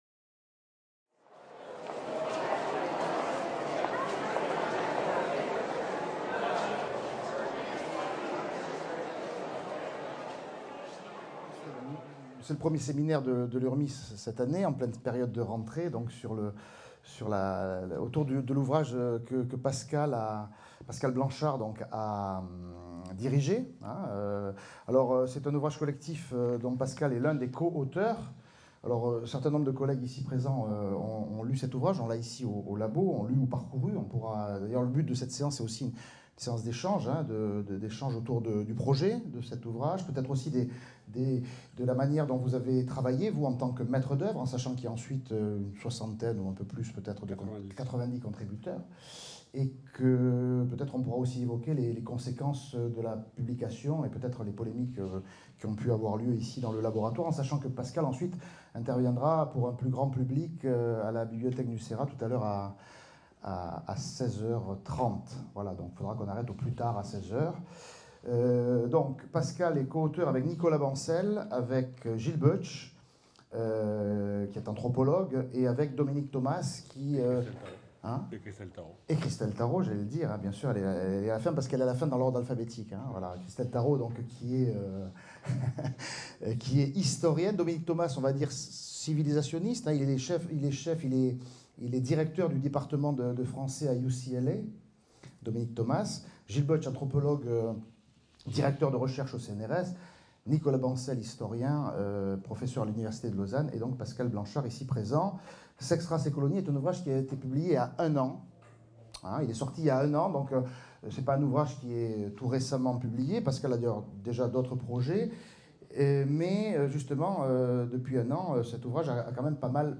Intervention de Pascal Blanchard (historien, chercheur associé au Laboratoire communication et politique, CNRS) autour de l’ouvrage Sexe, race et colonies (La Découverte, 2018) lors du séminaire Urmis Nice du 12 septembre 2019.